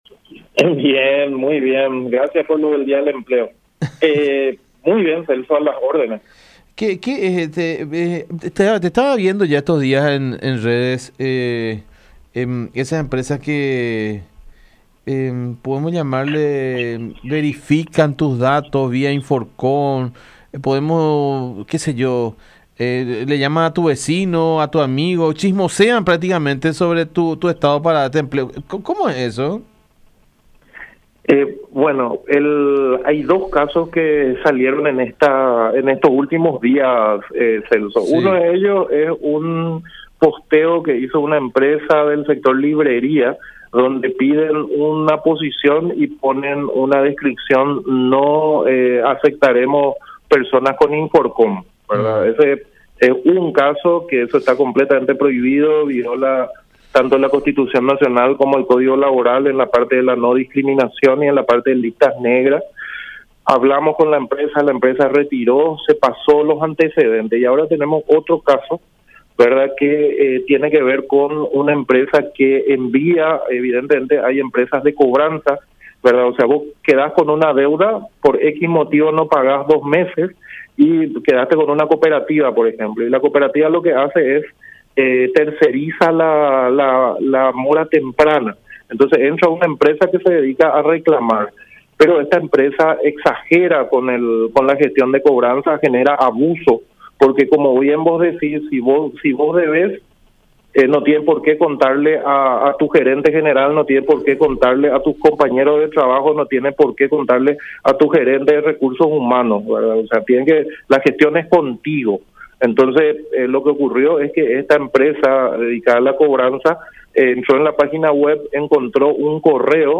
“Esta firma exagera con la gestión de cobranzas y genera abuso porque, si vos debés, no tiene por qué contarle a tu gerente de recursos humanos o a tus compañeros de trabajo”, expuso Enrique López Arce, director del Empleo del Ministerio del Trabajo, en contacto con La Unión.